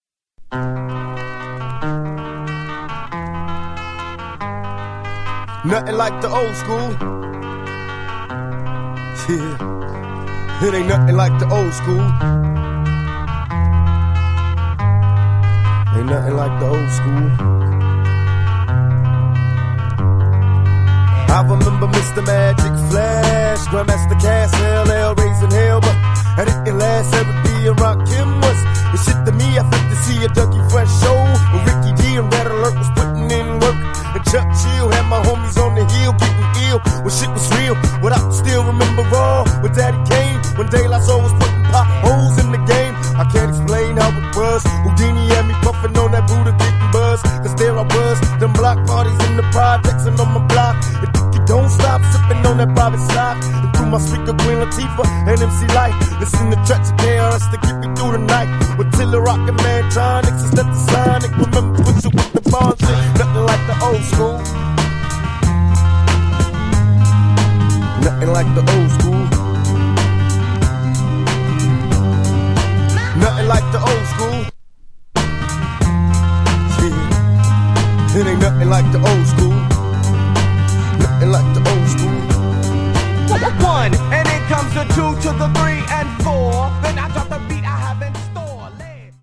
92 bpm
Dirty Version